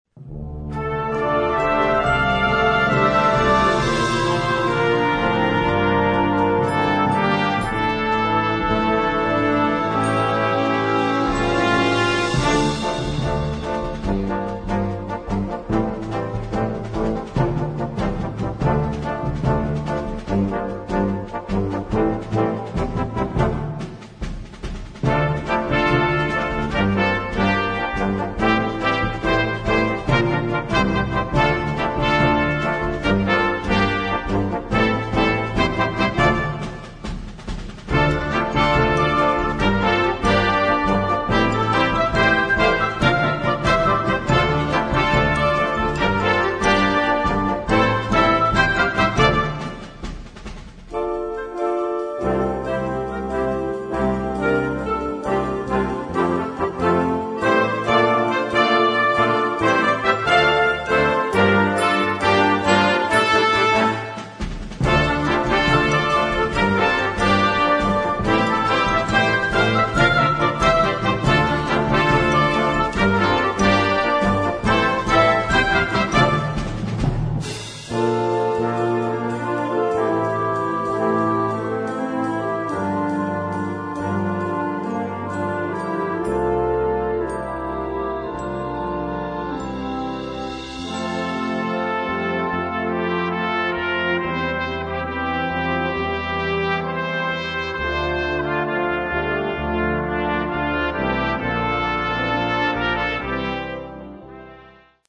overture for mid-level bands
Partitions pour orchestre d'harmonie des jeunes.